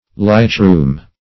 Lightroom \Light"room`\ (l[imac]t"r[=oo]m`), n.